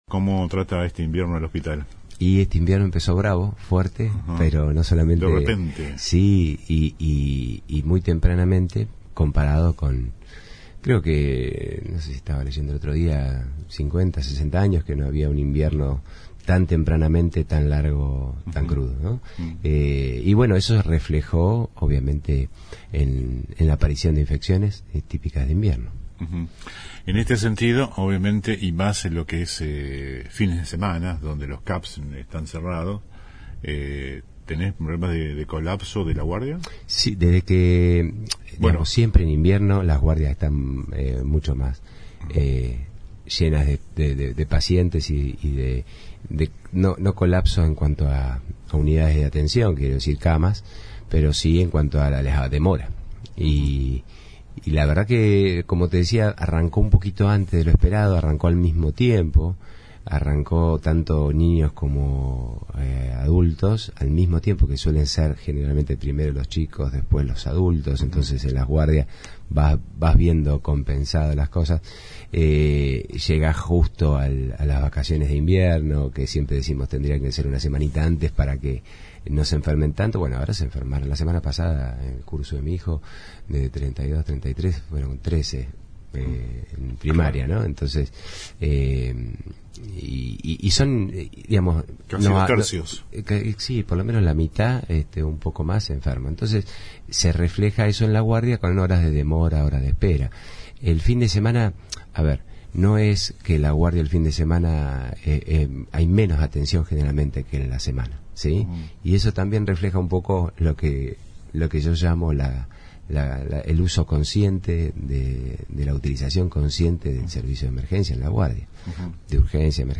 Este jueves, estuvo en el estudio de la radio el director del mayor efector de nuestro distrito, el Dr. Juan Tibiletti -en uso de licencia de la banca oficialista-, recordemos que ingresó como concejal, y luego se tomó licencia para asumir como director del hospital.
AUDIO COMPLETO DE LA ENTREVISTA A JUAN TIBILETTI